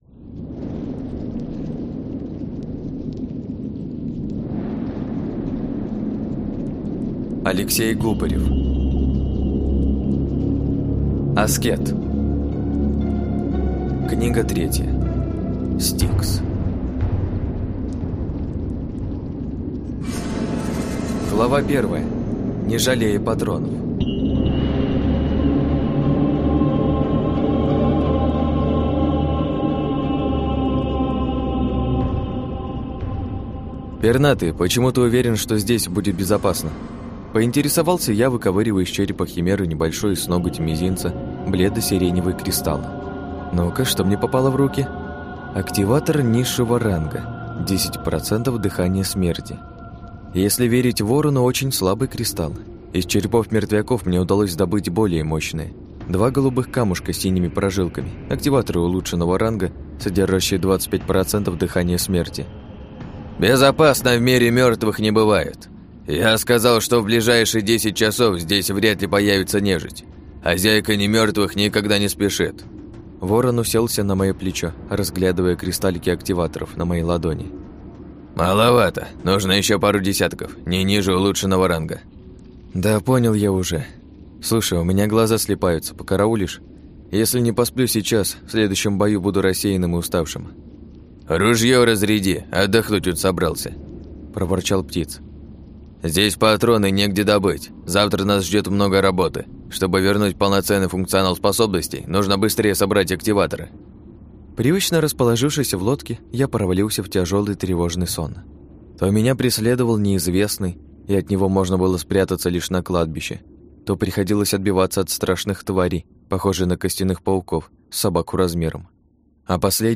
Аудиокнига Аскет. Стикс | Библиотека аудиокниг